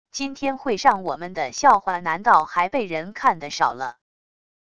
今天会上我们的笑话难道还被人看得少了wav音频生成系统WAV Audio Player